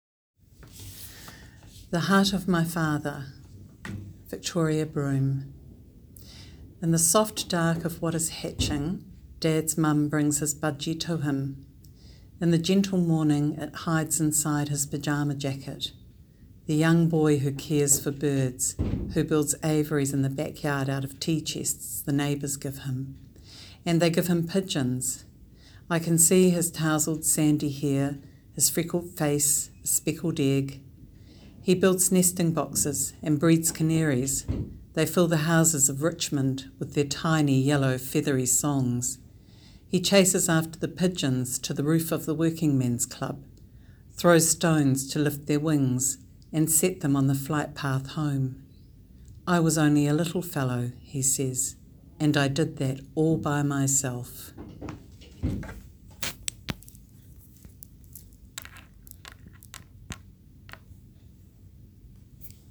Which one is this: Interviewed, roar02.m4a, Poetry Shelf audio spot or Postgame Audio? Poetry Shelf audio spot